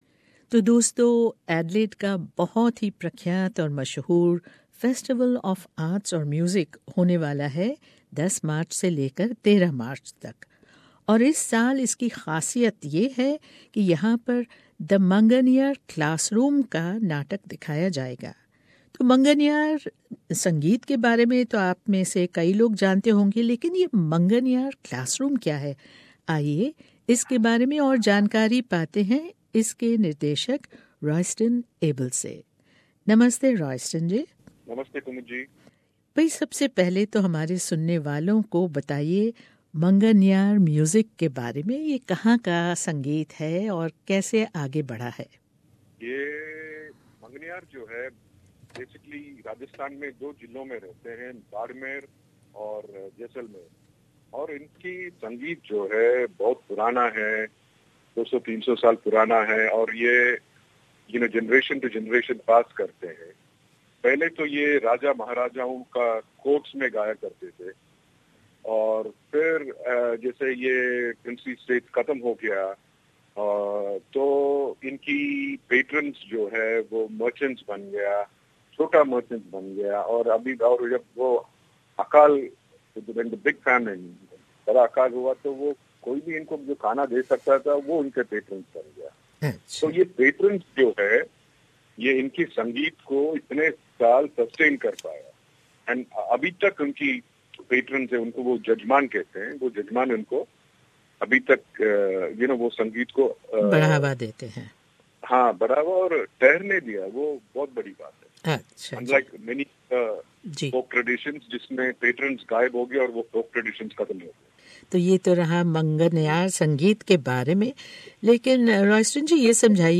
SBS Hindi